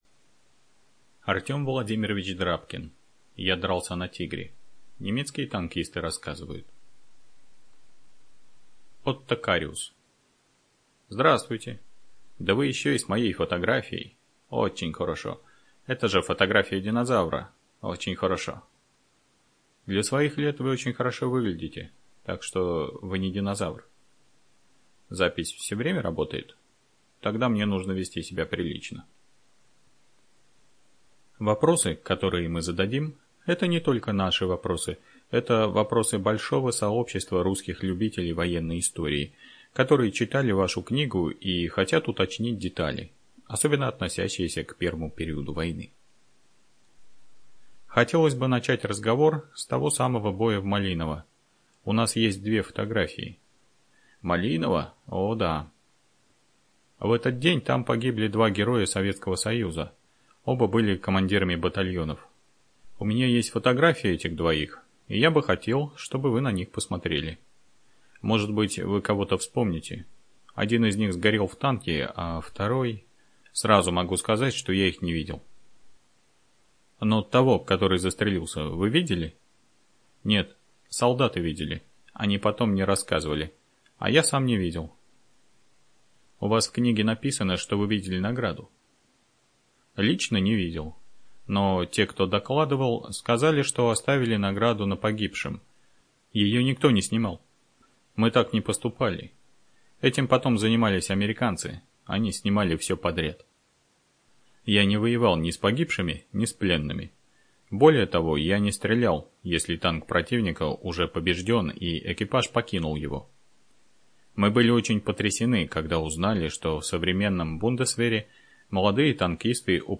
ЖанрБиографии и мемуары, Военная литература